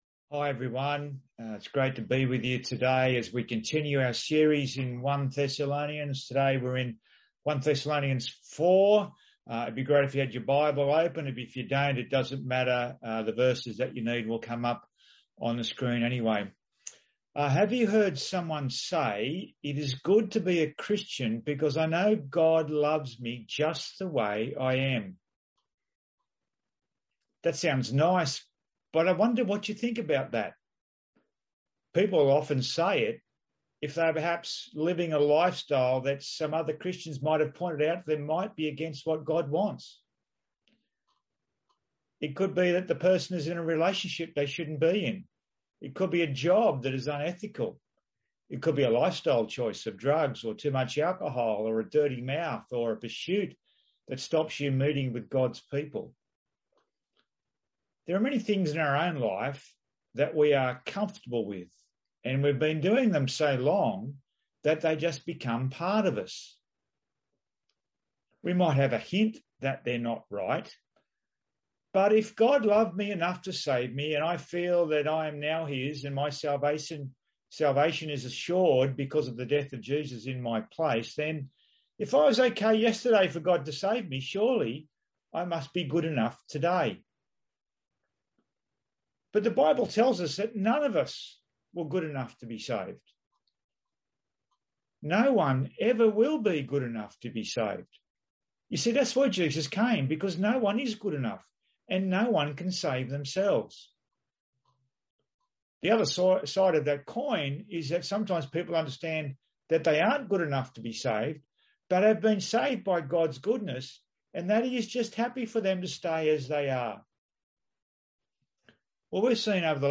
Click the ‘Download Sermon’ button or press ‘play’ in the audio bar above for an audio-only version of the sermon.